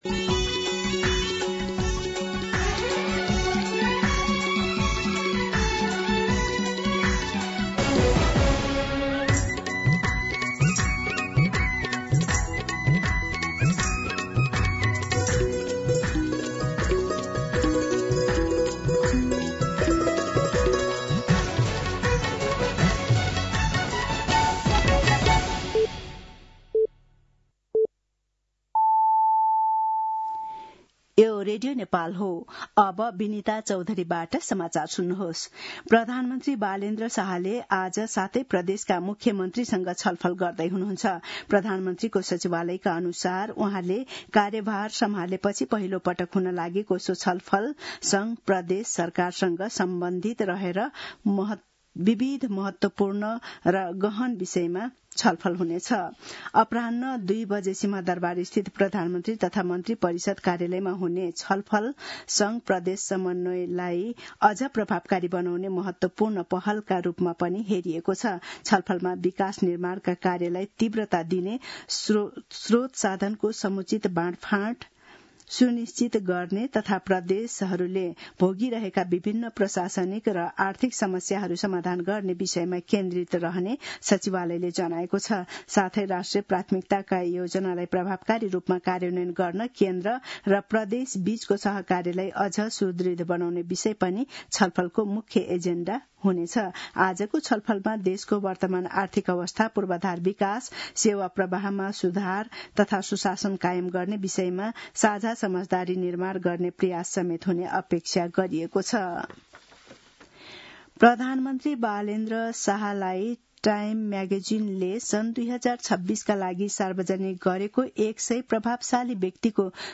मध्यान्ह १२ बजेको नेपाली समाचार : ३ वैशाख , २०८३
12-pm-Nepali-News.mp3